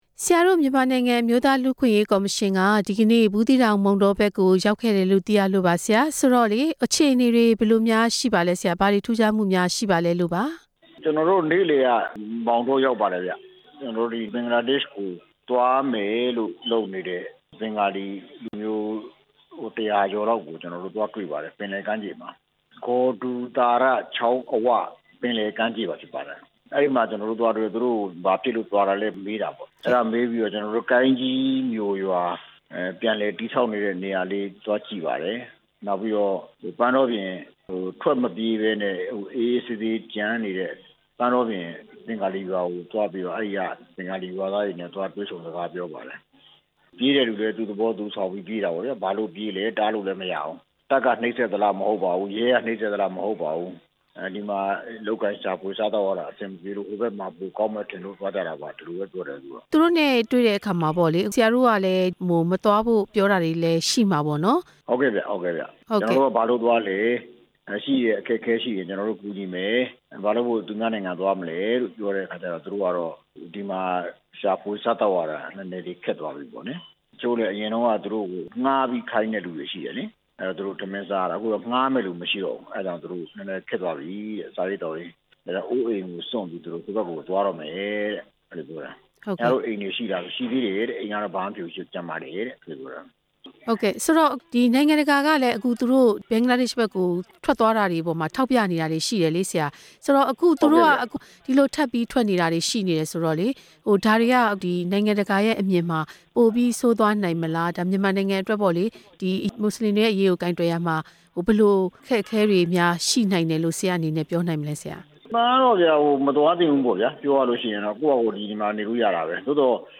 မြန်မာနိုင်ငံအမျိုးသားလူ့အခွင့်အရေးကော်မရှင်အဖွဲ့ဝင် ဦးယုလွင်အောင်နဲ့ မေးမြန်းချက်